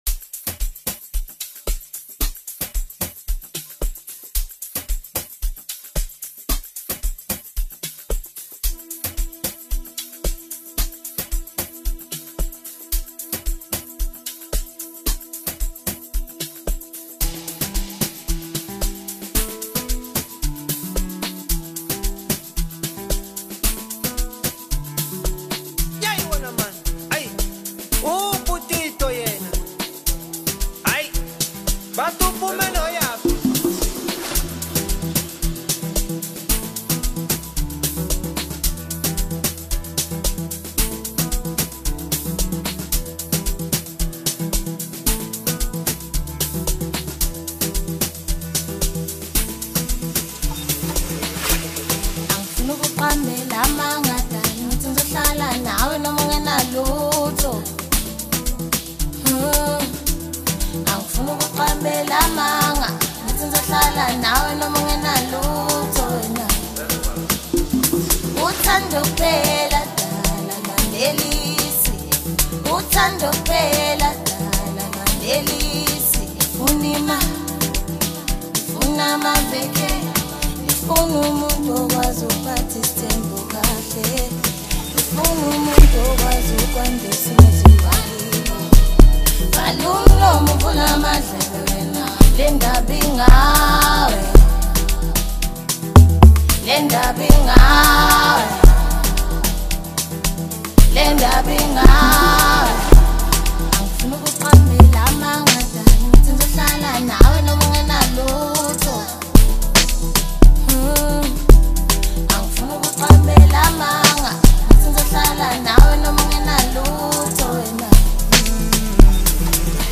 AMAPIANO Apr 07, 2026